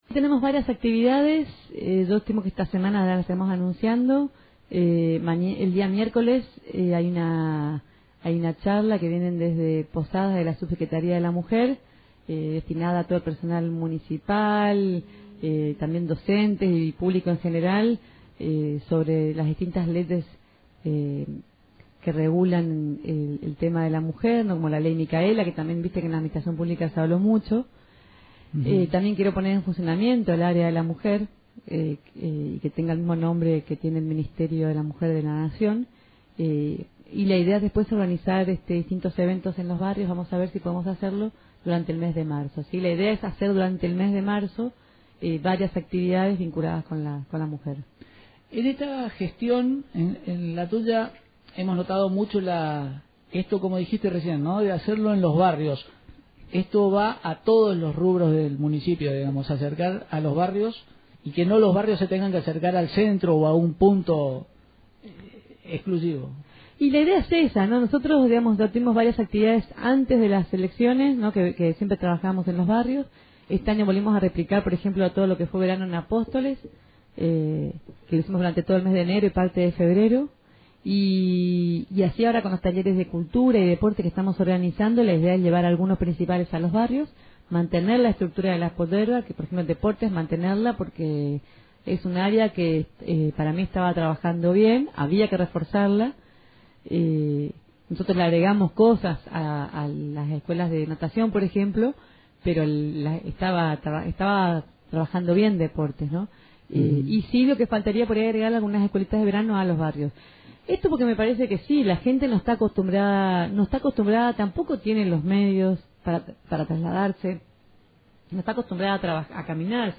La Intendente municipal detalló algunas de las actividades a realizar en la Capital Nacional de la Yerba Mate para conmemorar el “Día de la Mujer” con la firme idea de llevar actividades, vinculadas al tema, a todos los barrios.